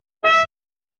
クラクション
honk.mp3